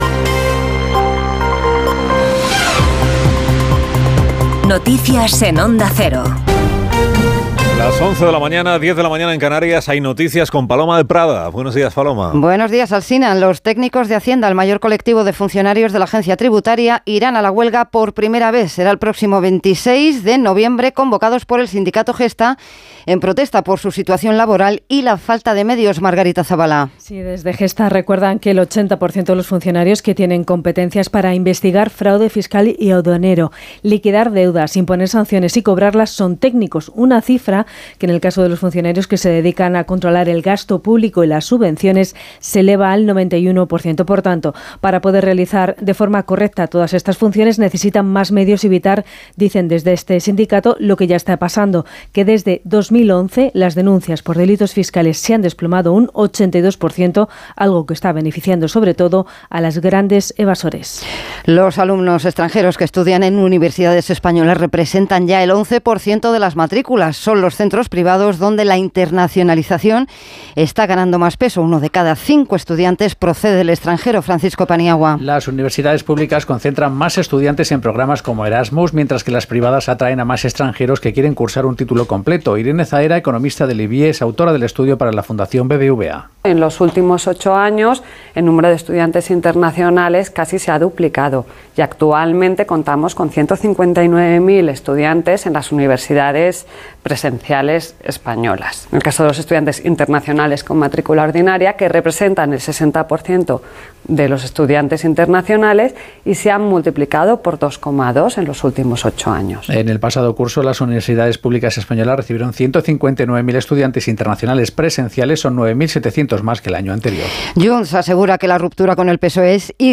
Conoce la ultima hora y toda la actualidad del dia en los boletines informativos de Onda Cero. Escucha hora a hora las noticias de hoy en Espana y el mundo y mantente al dia con la informacion deportiva.